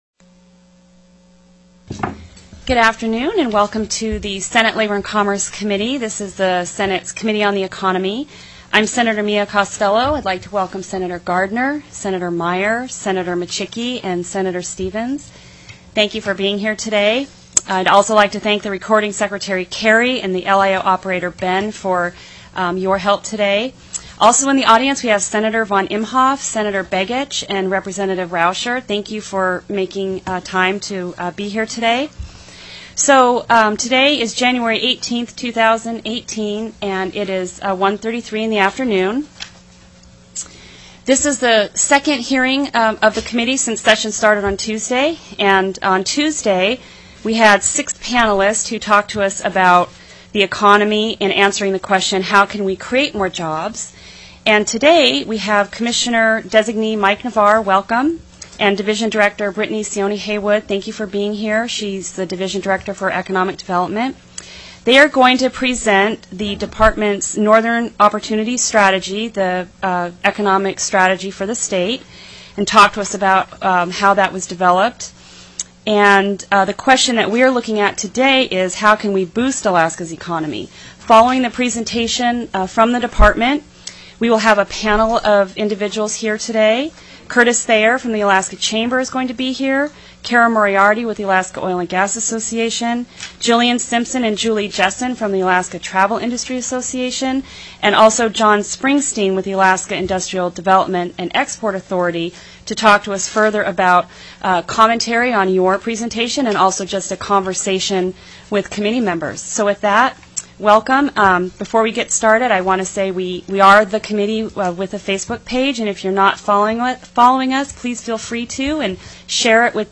01/18/2018 01:30 PM Senate LABOR & COMMERCE
The audio recordings are captured by our records offices as the official record of the meeting and will have more accurate timestamps.